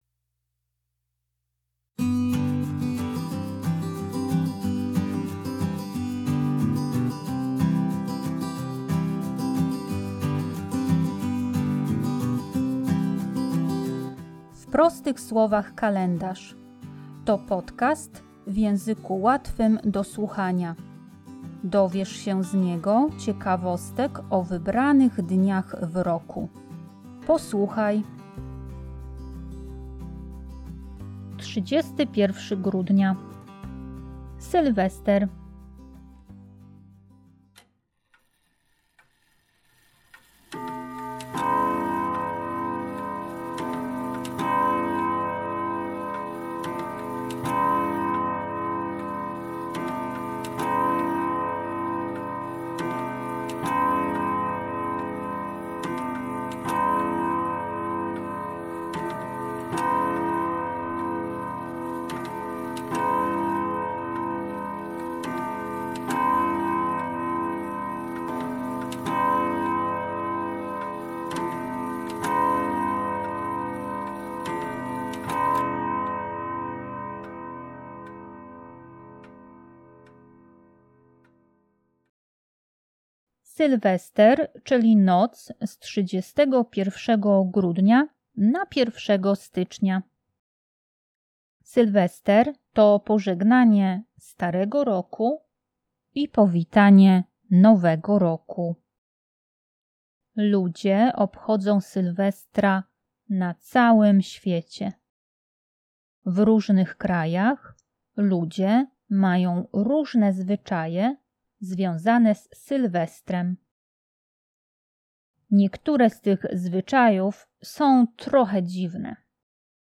W podcaście usłyszycie dźwięki tykającego zegara.